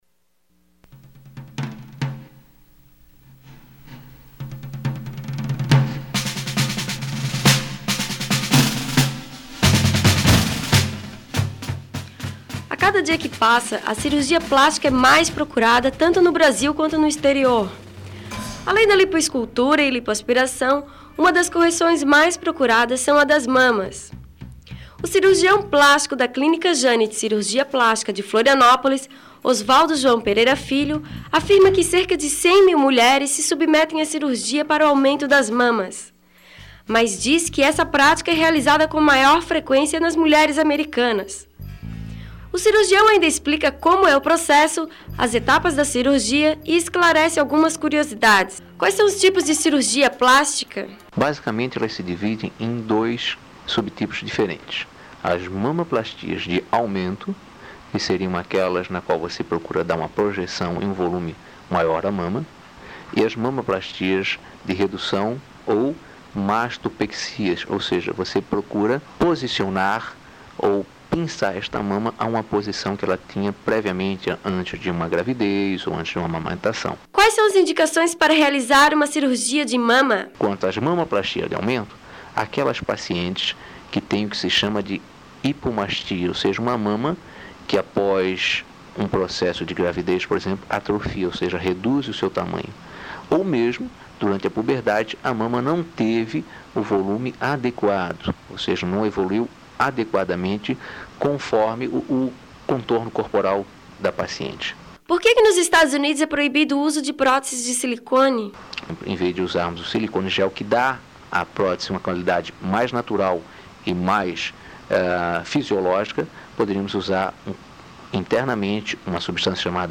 Abstract: Neste programa, os tipos mais comuns de cirurgias plásticas e entrevistas com cirurgiões.